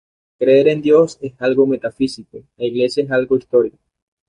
me‧ta‧fí‧si‧co
/metaˈfisiko/